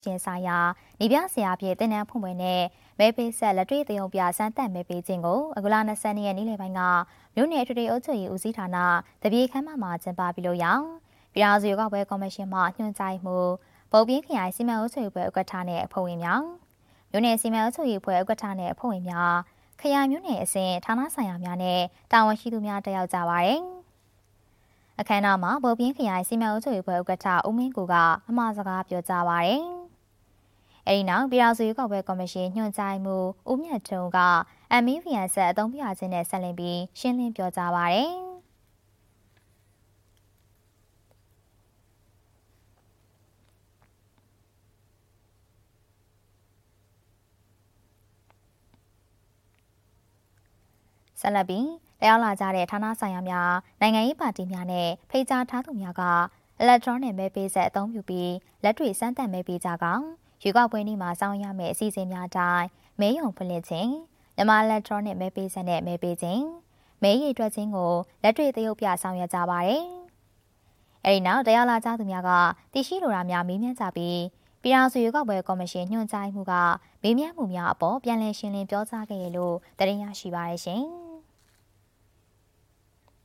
တနင်္သာရီတိုင်းဒေသကြီး၊ ဘုတ်ပြင်းမြို့နယ်တွင် မြန်မာအီလက်ထရောနစ်မဲပေးစက်(MEVM)ကိုင်တွယ်အသုံးပြုခြင်းဆိုင်ရာ နည်းပြဆရာဖြစ်သင်တန်းဖွင့်ပွဲနှင့်မဲပေးစက်လက်တွေ့သရုပ်ပြ စမ်းသပ်မဲပေးခြင်းအခမ်းအနားကျင်းပသည်။ နေပြည်တော်၊ ဇူလိုင် ၂၃ ရက်(MWD)